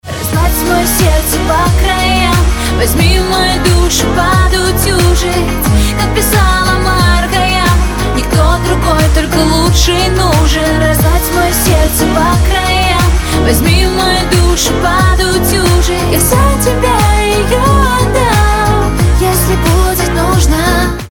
• Качество: 256, Stereo
поп
красивые
женский вокал
романтические